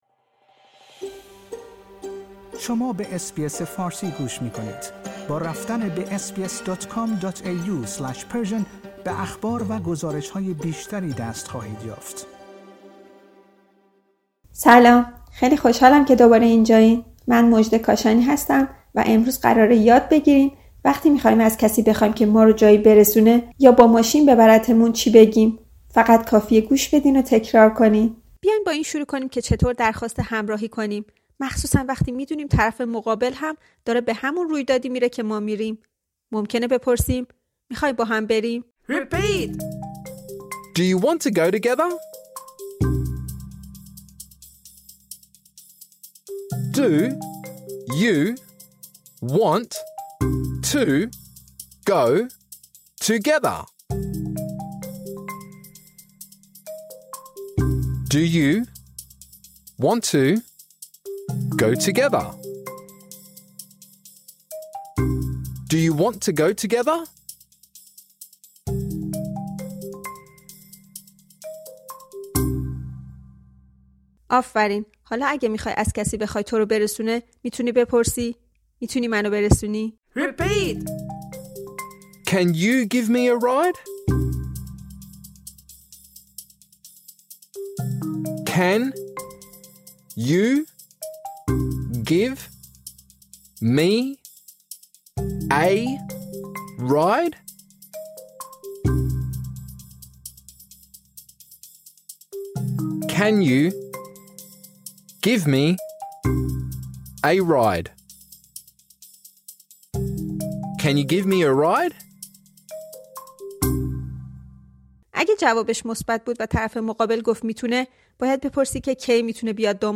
این درس برای زبان‌آموزان سطح آسان طراحی شده است.